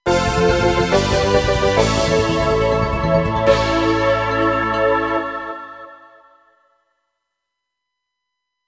私のオリジナル曲のうち、ゲームミュージック風の曲を公開いたします。